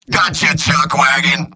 Robot-filtered lines from MvM. This is an audio clip from the game Team Fortress 2 .
Engineer_mvm_dominationheavy05.mp3